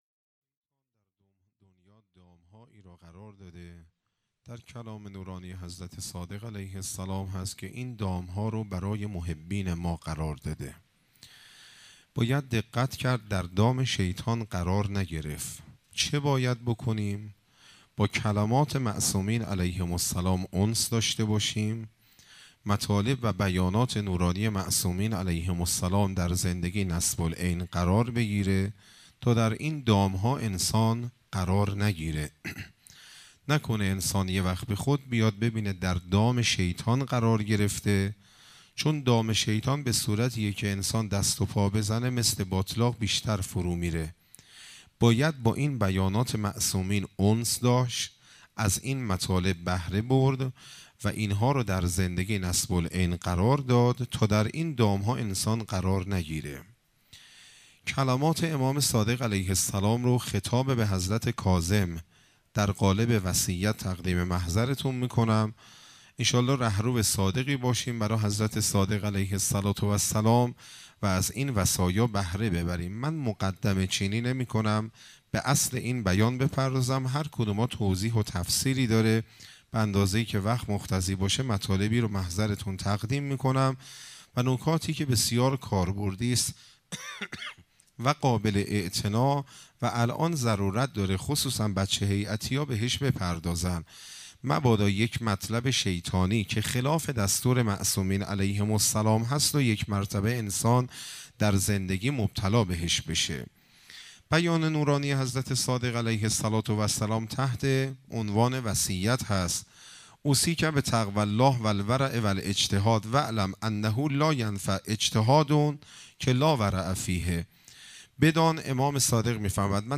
شهادت امام صادق علیه السلام 96 - هیئت مکتب المهدی عجل الله تعالی فرجه الشریف
سخنرانی